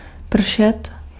Ääntäminen
IPA: [plø.vwaʁ]